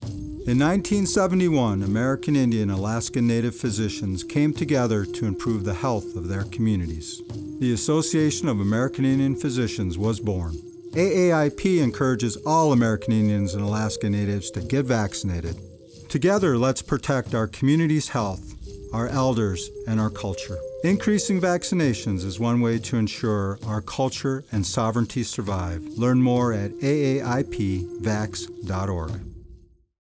Radio / Televsion PSAs